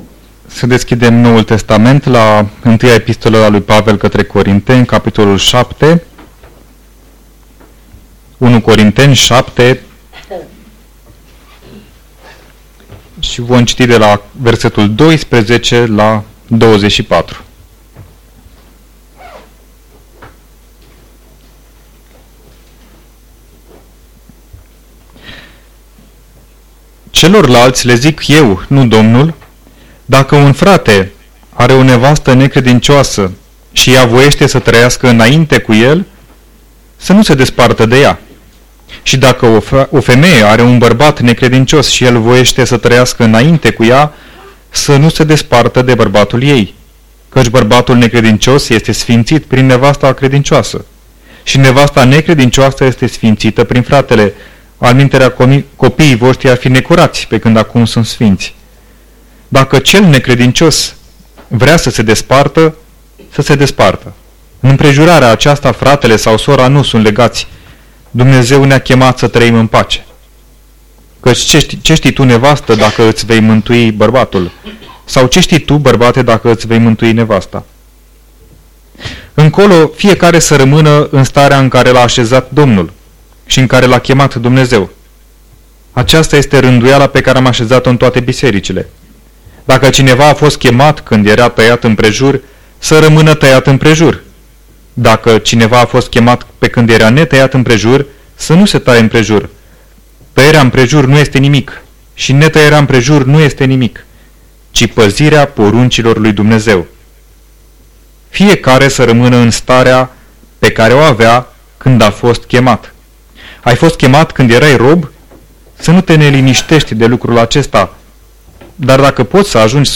Tineret, seara Predică